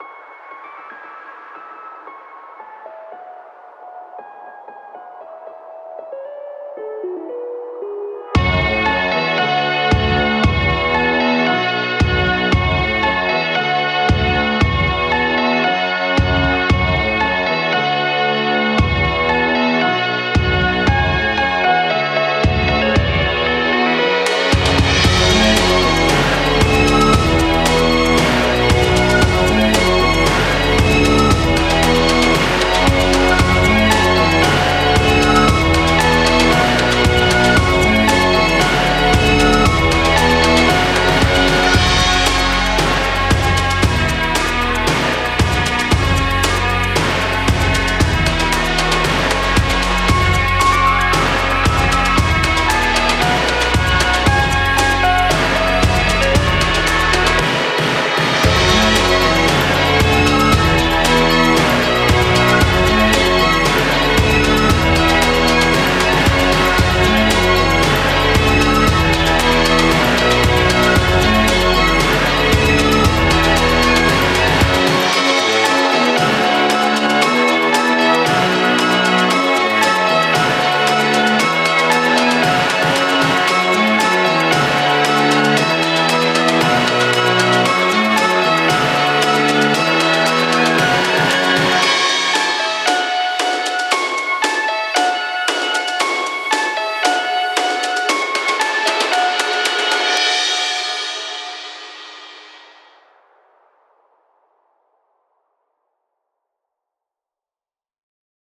orchestralsong_4.mp3